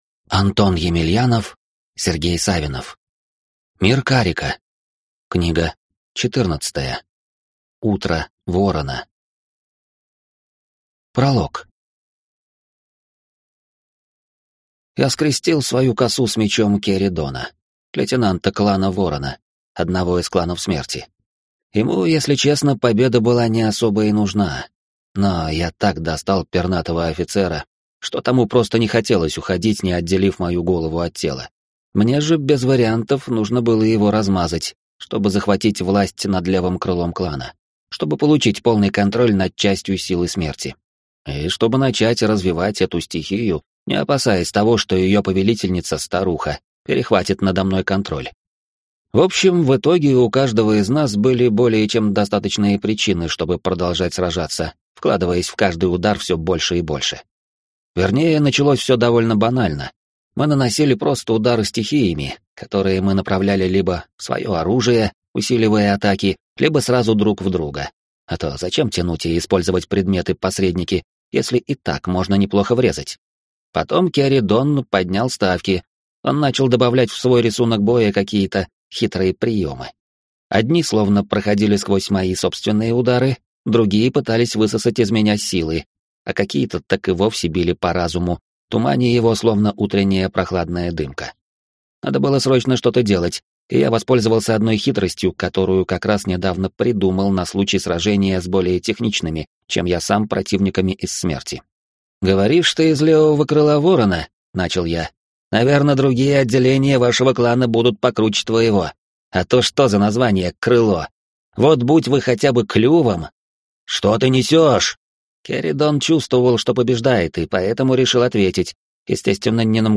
Аудиокнига Утро ворона | Библиотека аудиокниг